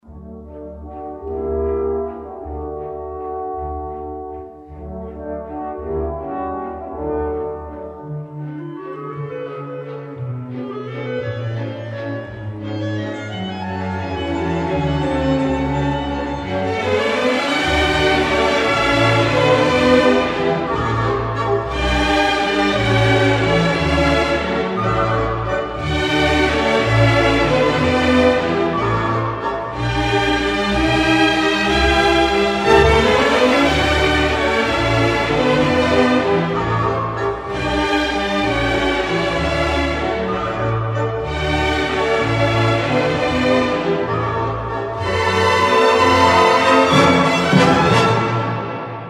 Категория: Классические | Дата: 09.12.2012|